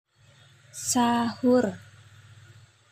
Contoh pengucapan